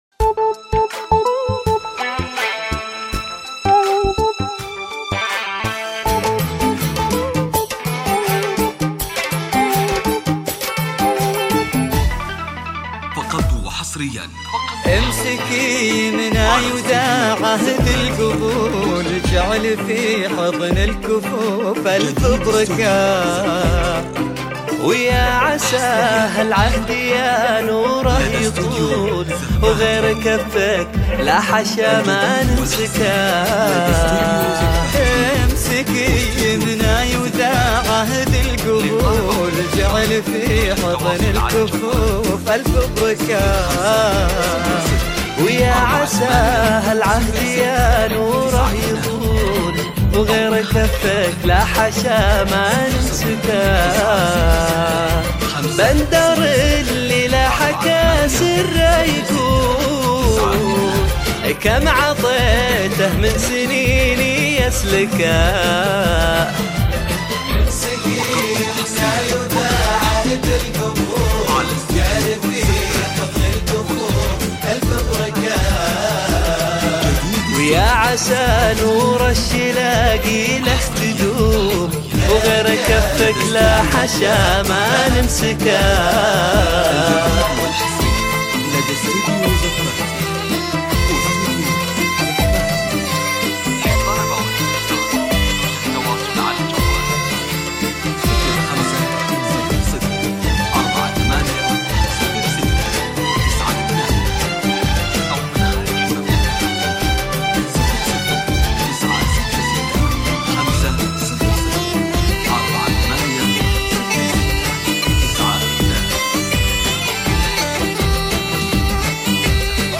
زفة كوشة – تنفيذ بالاسماء